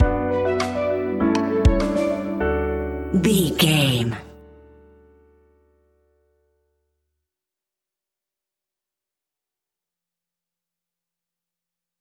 Joyful Piano Track Stinger.
Ionian/Major
pop
pop rock
energetic
uplifting
upbeat